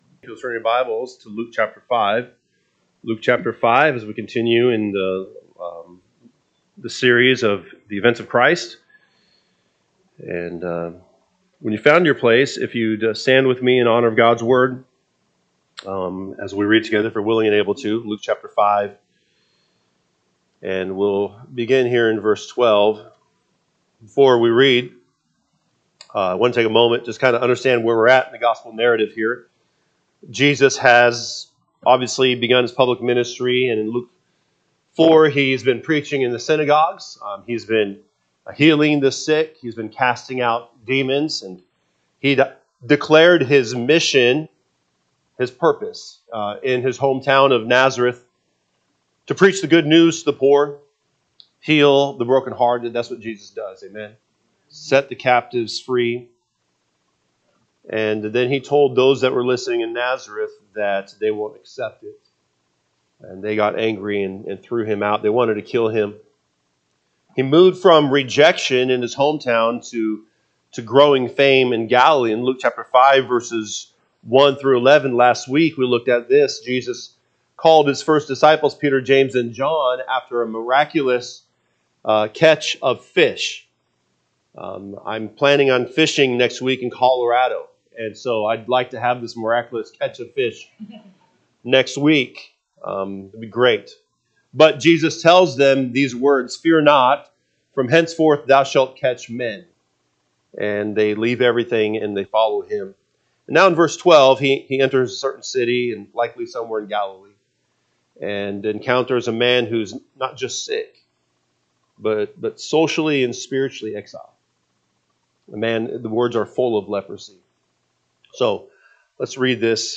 August 24, 2025 am Service Luke 5:12-15 (KJB) 12 And it came to pass, when he was in a certain city, behold a man full of leprosy: who seeing Jesus fell on his face, and besought him, saying, …
Sunday AM Message